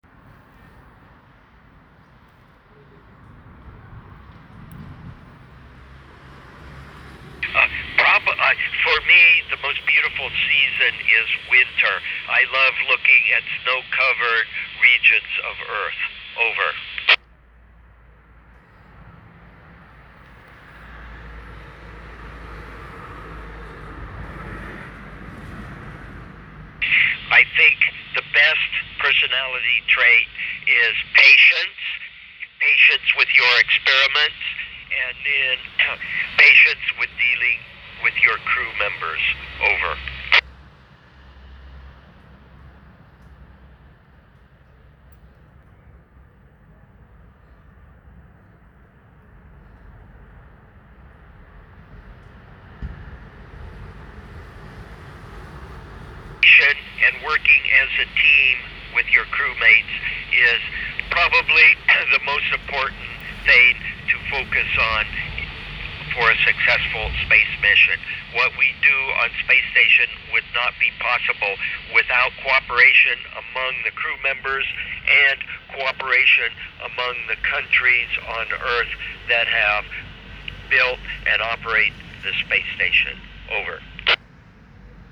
saját rádióval és antennával figyeltük az ISS jeleit (Kép lehetne a rádióról, antennáról)
ARISS iskolai kapcsolatokat hallgattunk (ehhez van hanganyagom Butch  Wilmore hangjával)